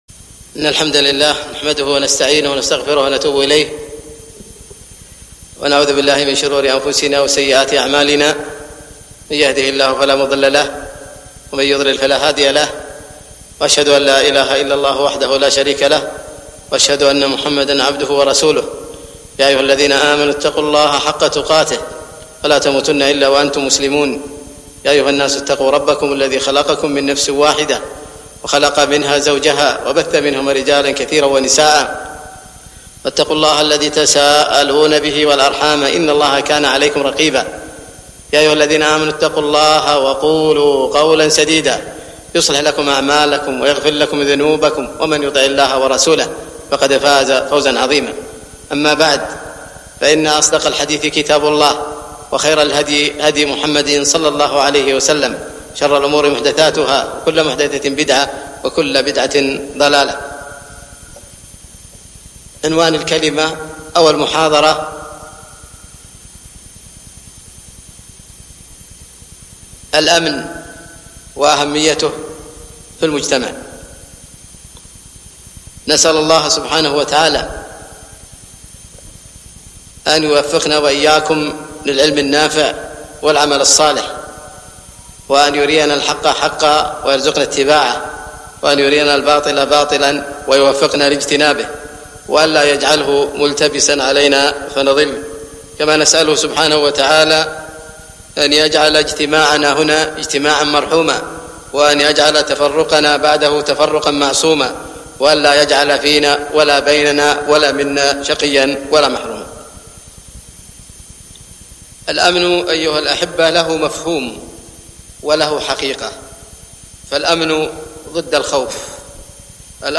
محاضرة - الأمن الفكري وأثره على المجتمع ١٤٢٨